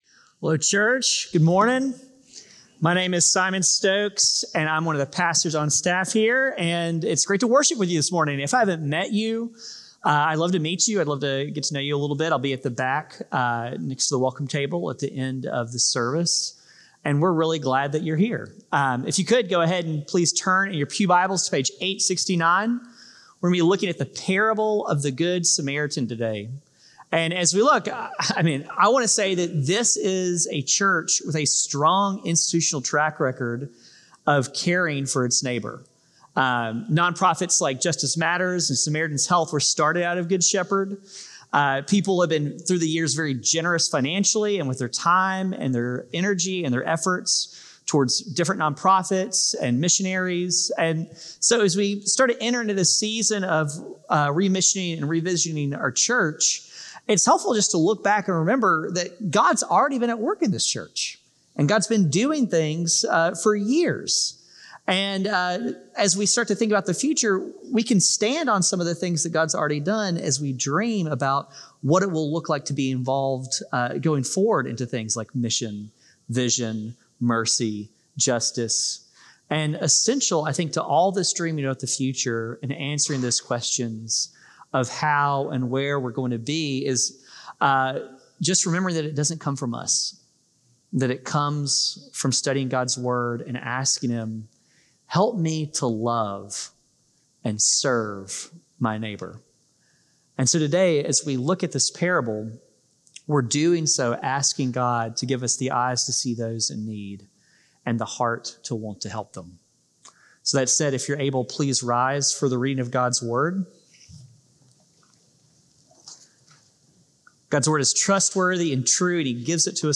CGS-Service-8-10-25-Podcast.mp3